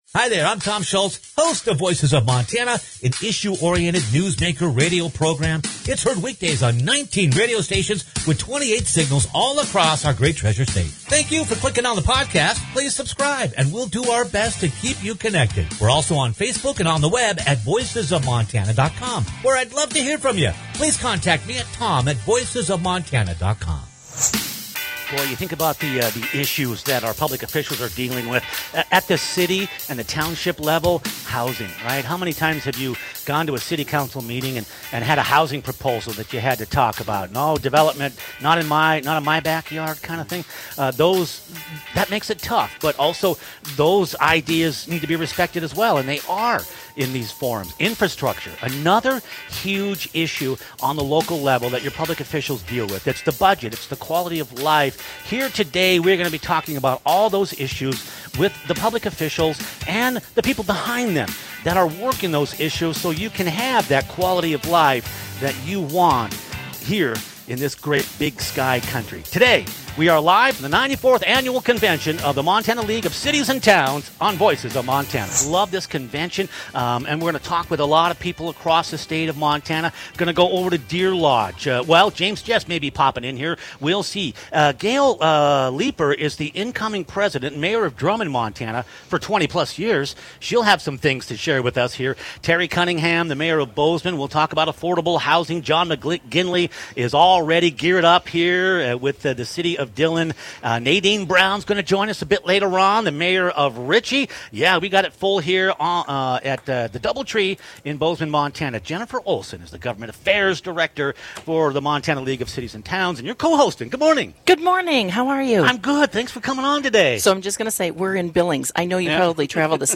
Click on the podcast for community check-ins from Mayors in Dillon, Bozeman, Drummond and Richey, and to hear more about housing solution ideas generated locally, but also as part of new policy directives